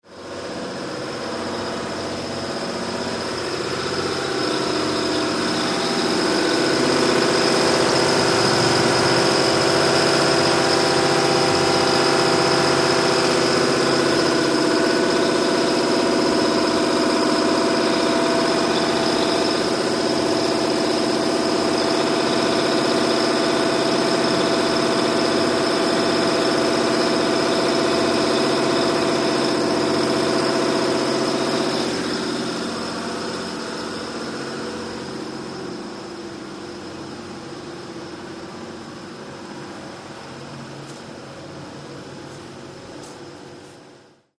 MACHINES - CONSTRUCTION & FACTORY GENERATOR: INT: Motor idle, low high end whistle, movement towards end.